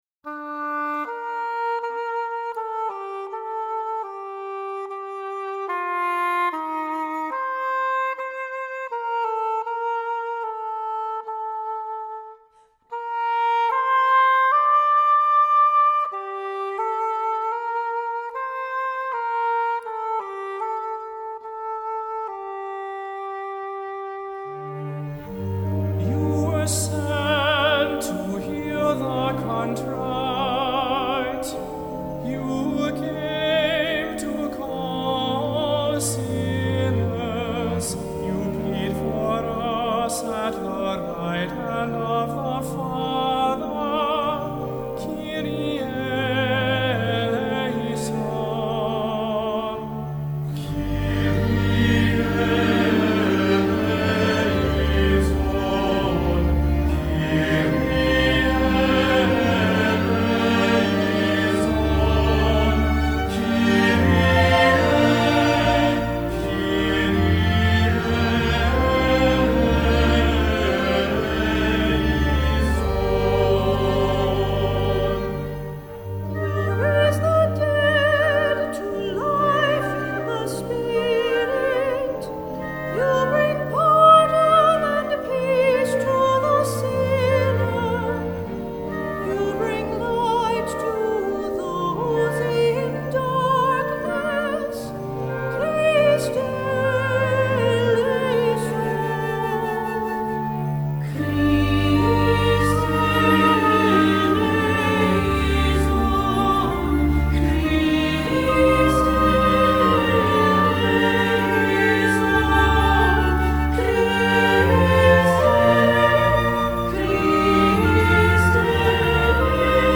Voicing: SATB; Cantors; Assembly